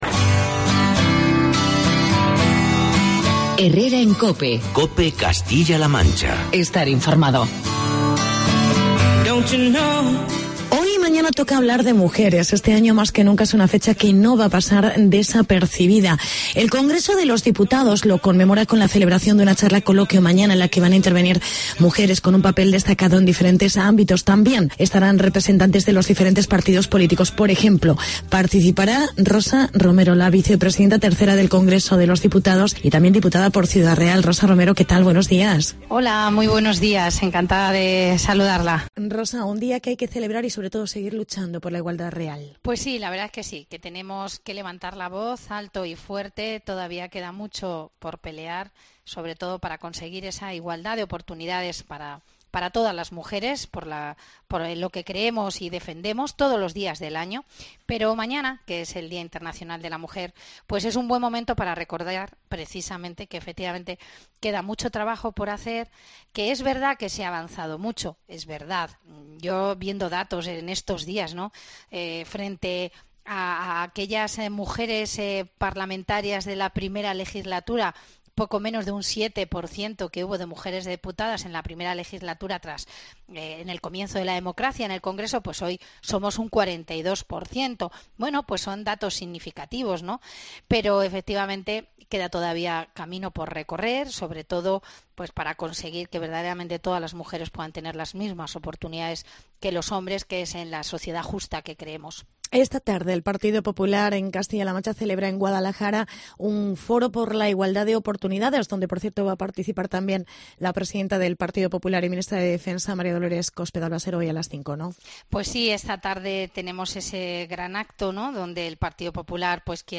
Entrevista con Rosa Romero, vpta 3ª del Congreso de los Diputados.